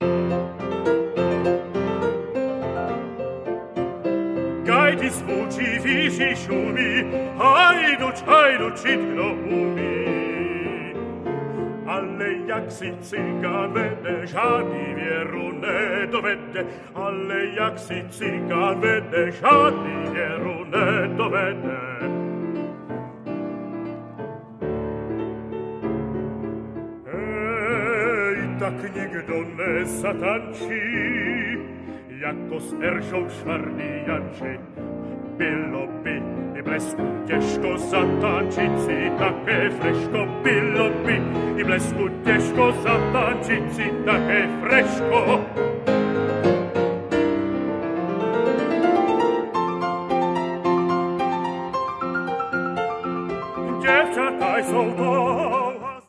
zpěv
klavír